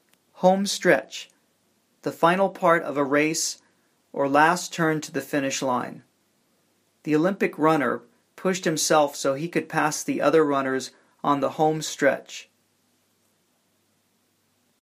英語ネイティブによる発音は下記のリンクをクリックしてください。
Homestretch.mp3